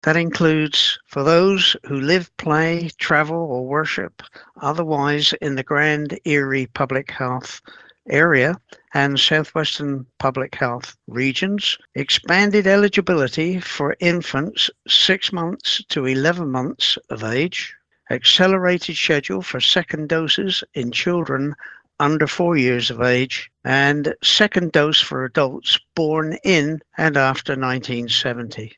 In a joint media briefing on Thursday, Grand Erie Public Health and Southwestern Public Health, shared an update on the current measles situation in the area.
To combat the outbreak, Ontario’s Ministry of Health and Public Health Ontario have expanded measles vaccine eligibility in the region, effective immediately. Dr. Malcolm Locke, Acting Medical Officer of Health for Grand Erie Public Health, explains further.